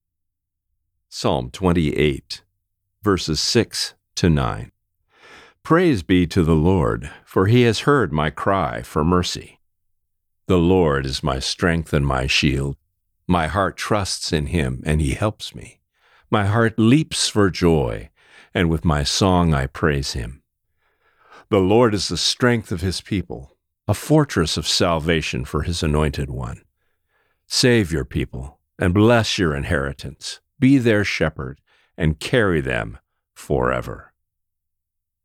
Reading: Psalm 28:6-9